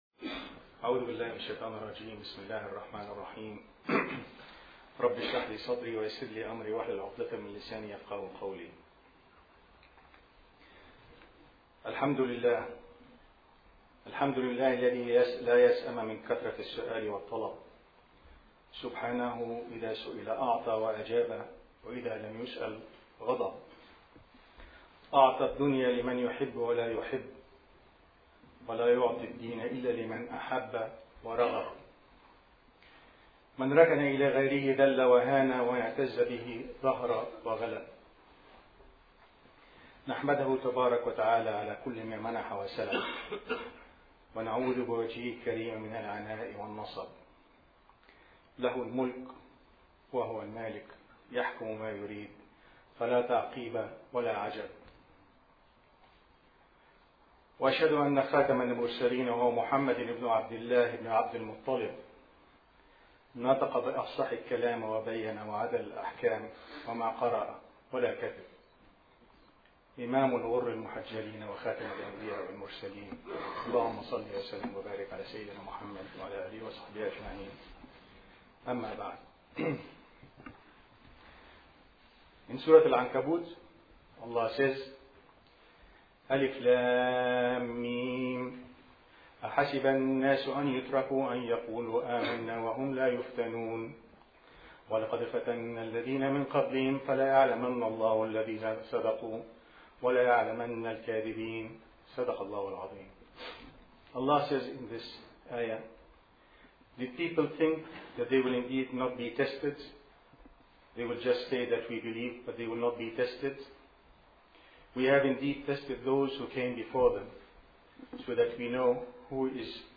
Audio Khutbah